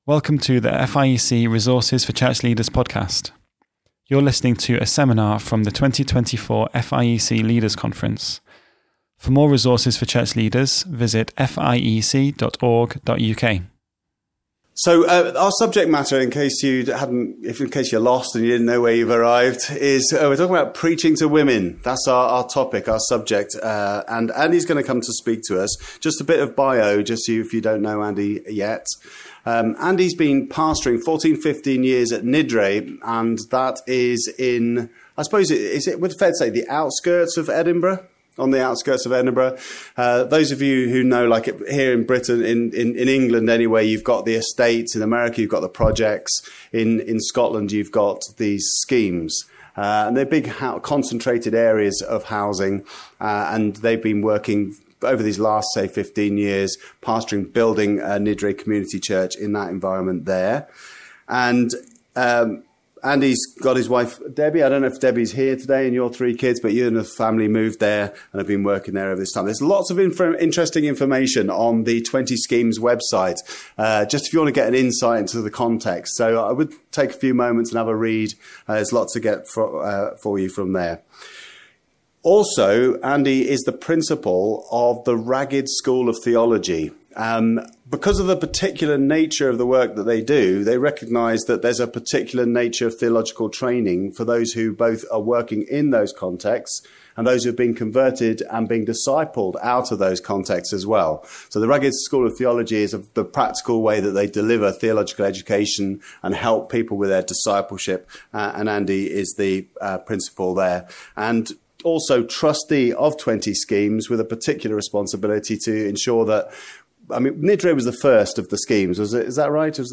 How do men teach women and how can they get better at it? A seminar from the 2024 Leaders' Conference.